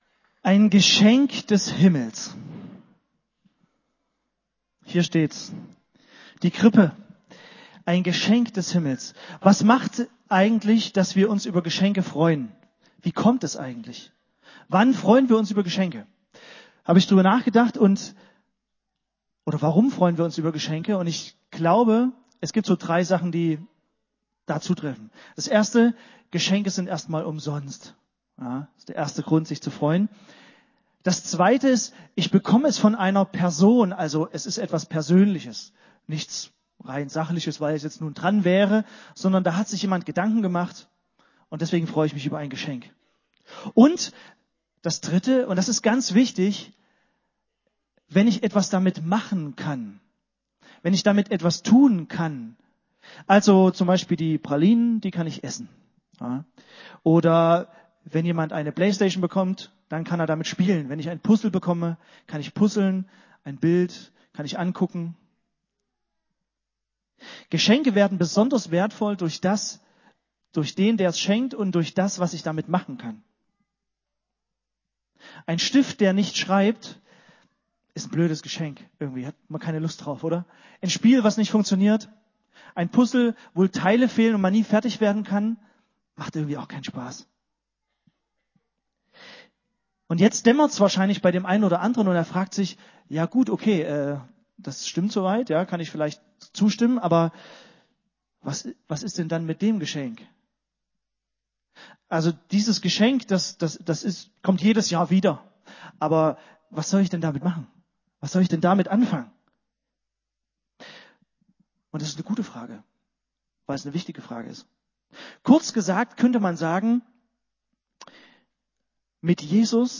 Am 24. Dezember erlebten wir einen schönen und runden Heilig-Abend-Gottesdienst.